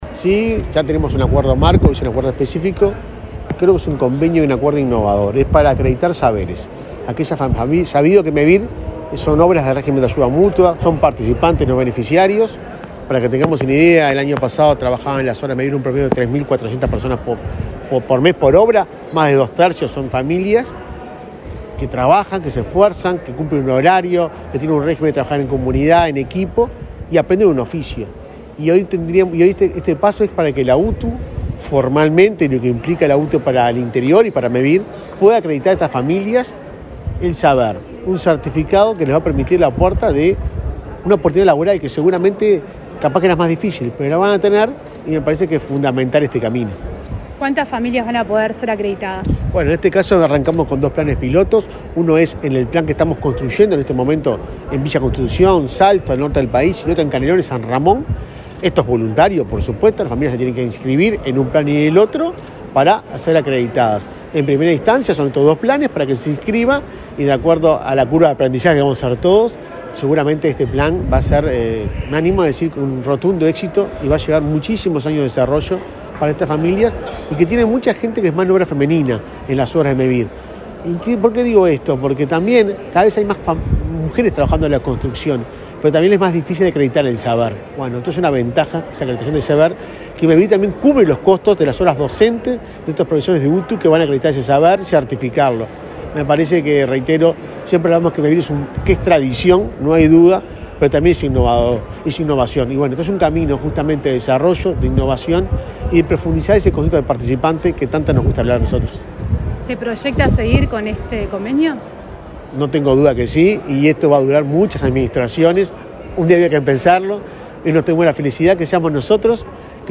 Entrevista al presidente de Mevir, Juan Pablo Delgado
El presidente de Mevir, Juan Pablo Delgado, dialogó con Comunicación Presidencial, luego de firmar un acuerdo con el director general de UTU, Juan Pereyra, a través del cual la institución educativa acreditará saberes adquiridos en la construcción, de 20 personas de las localidades de Villa Constitución, en el departamento de Salto, y de San Ramón, en Canelones.